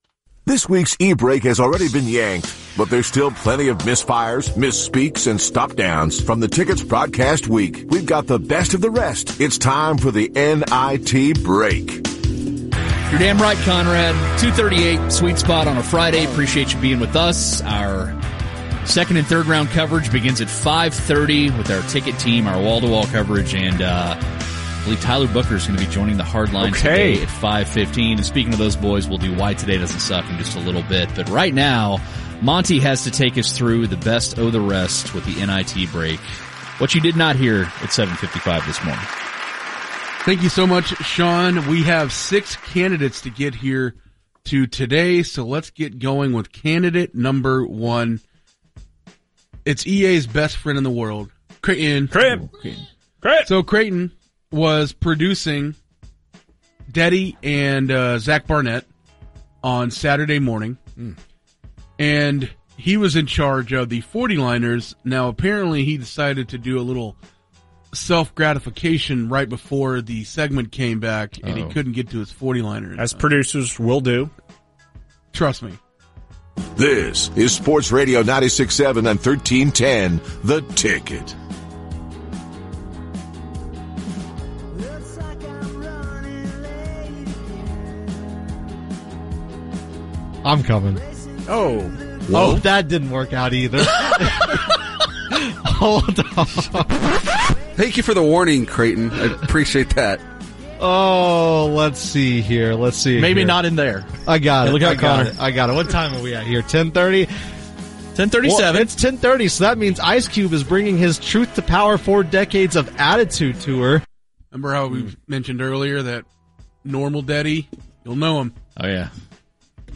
fake Shannone Sharpe